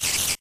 spider4.ogg